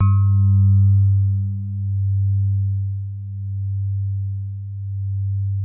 E-PIANO 1
TINE SOFT G1.wav